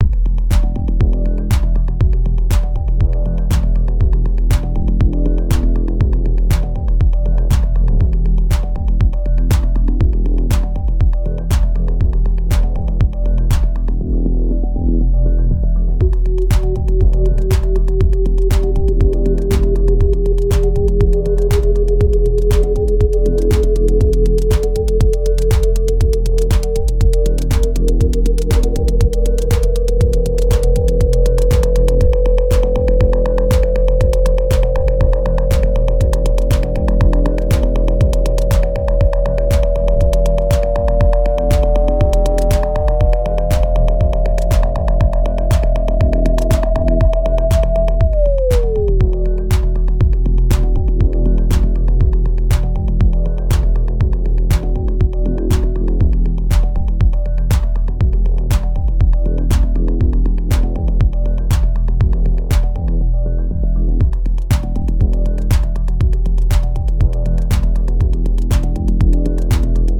I definitely need to use some white noise or something to send through it but just to make sure it was working, I sent a sine wave through it tonight.
I’ll try to get a video together but for now, here’s this crappy live jam!